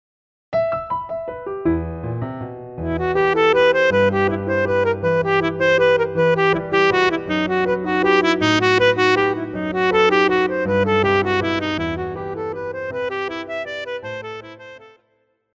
Classical
Saxophone-Alto
Piano
Etudes
Solo with accompaniment